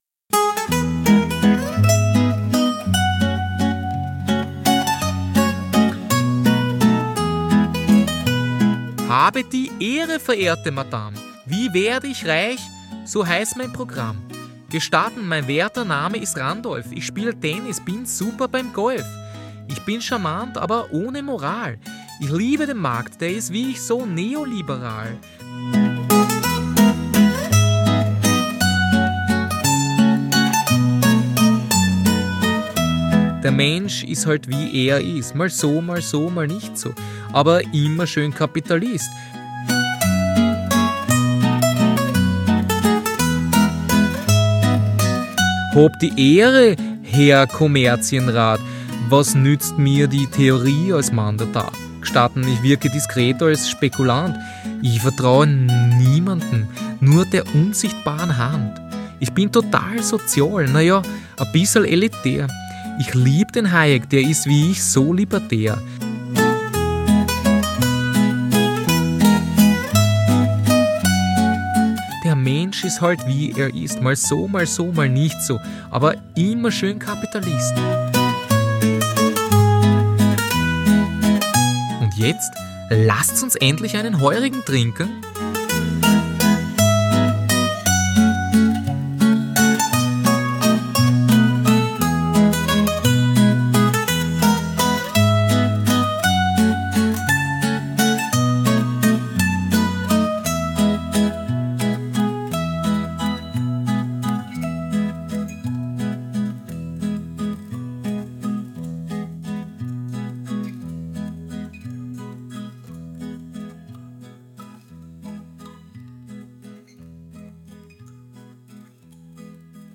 Von Hayek zeigt ab und zu aber Gemüt – was sich auch in dieser kleinen musikalischen Selbstauskunft niederschlägt. Diese wird, wie es sich gehört, in Form eines österreichischen Zither-Stücks dargeboten.